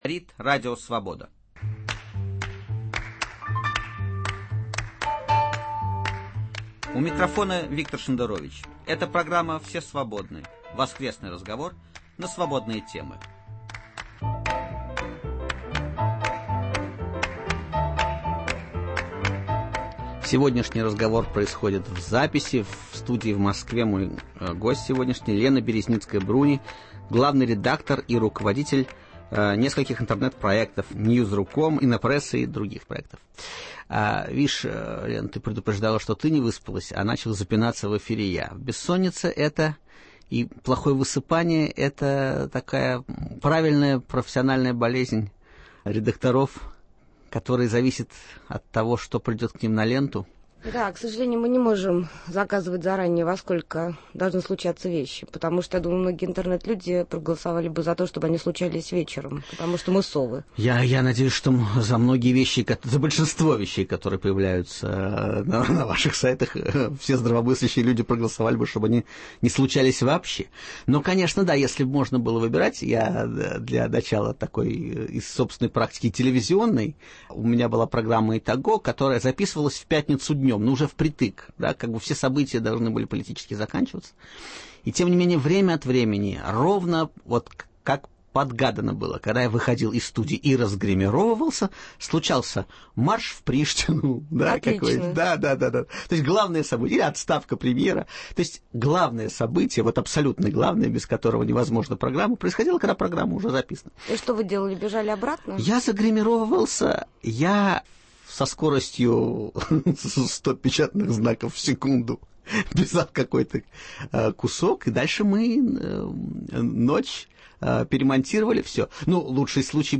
В гостях у Виктора Шендеровича